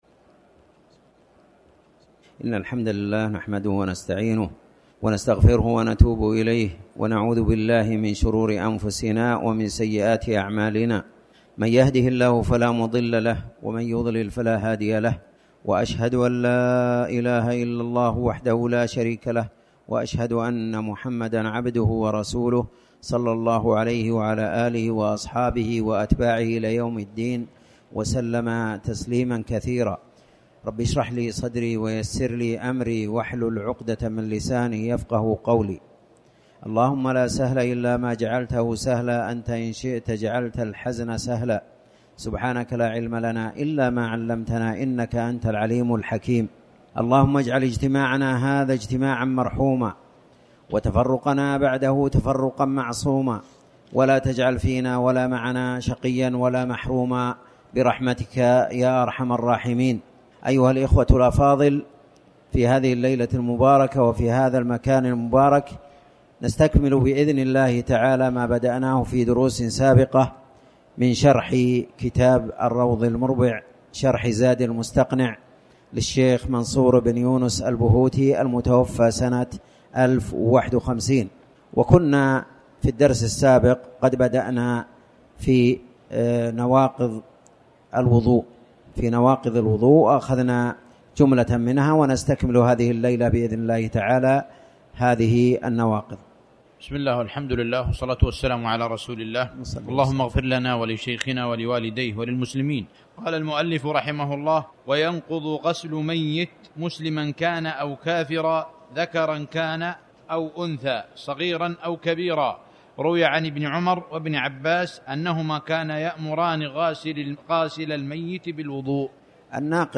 تاريخ النشر ٤ ربيع الأول ١٤٤٠ هـ المكان: المسجد الحرام الشيخ